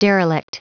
Prononciation du mot derelict en anglais (fichier audio)
Prononciation du mot : derelict